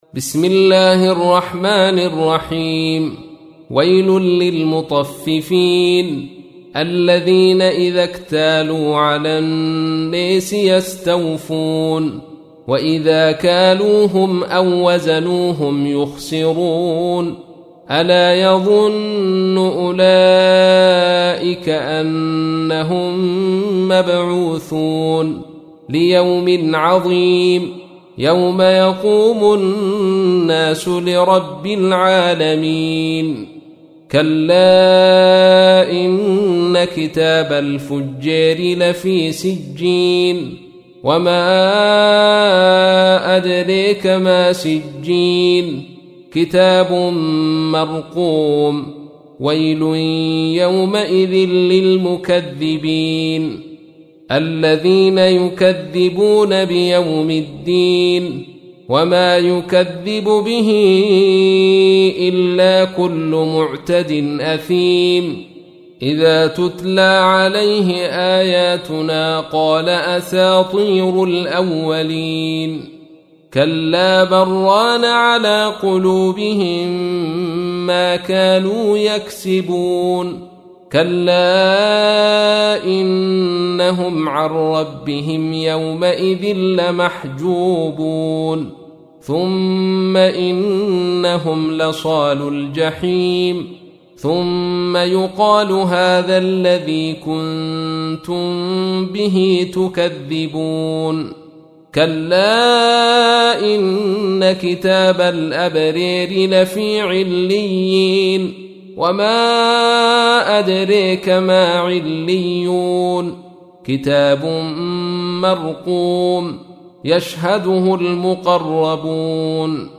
تحميل : 83. سورة المطففين / القارئ عبد الرشيد صوفي / القرآن الكريم / موقع يا حسين